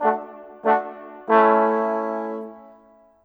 Rock-Pop 06 Brass 02.wav